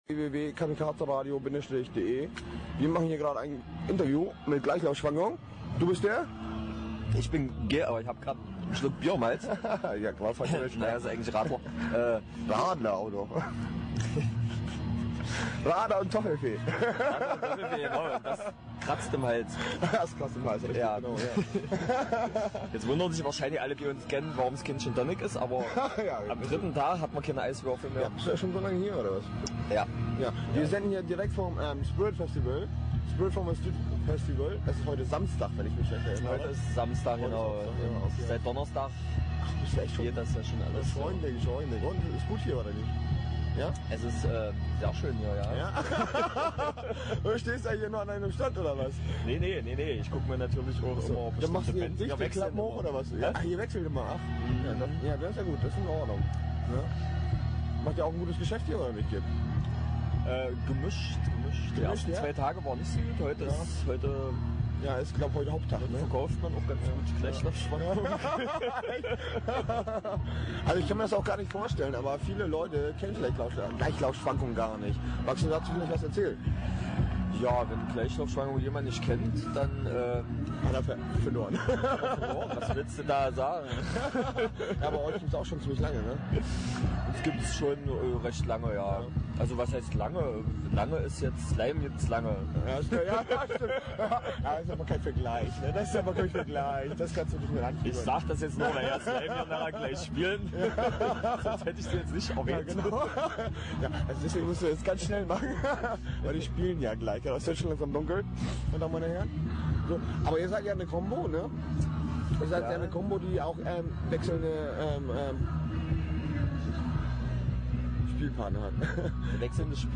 Interview Teil 1 (11:13)